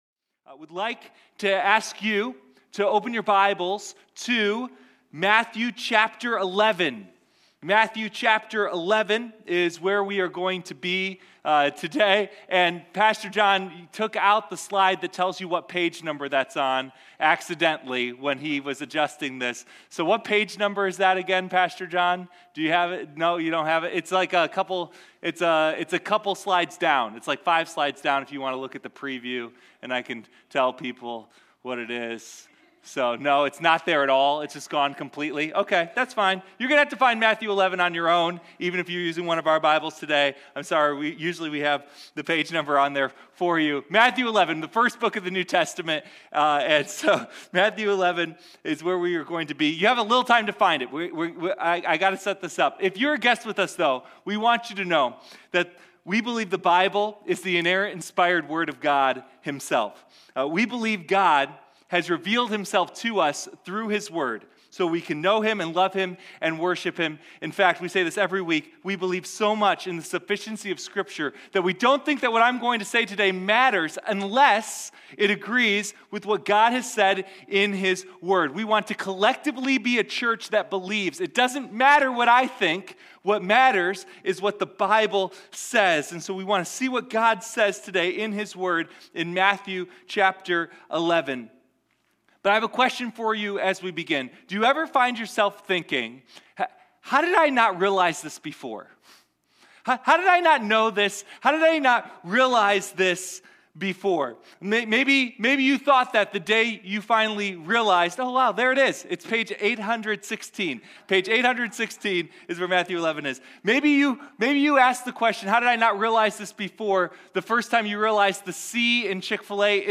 Sunday Morning Questioning the King